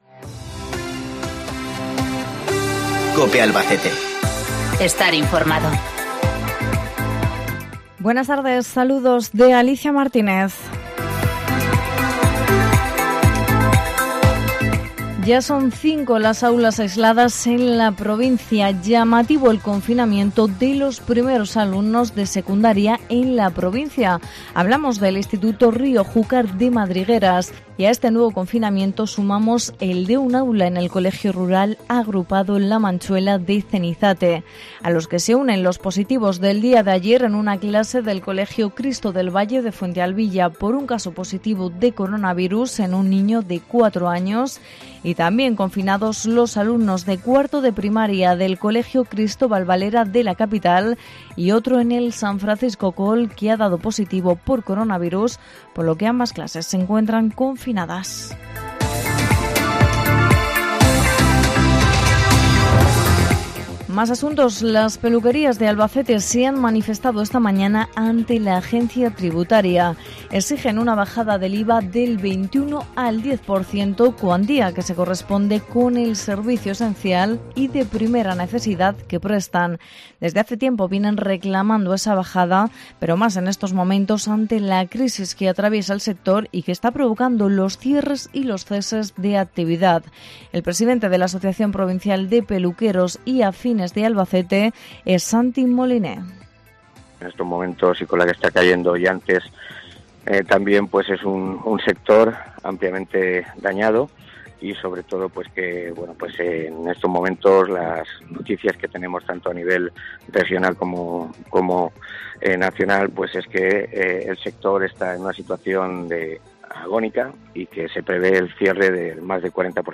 INFORMATIVO LOCAL 14:20H